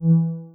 Radar2.wav